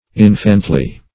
Infantly \In"fant*ly\, a.
infantly.mp3